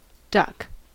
duck (6).mp3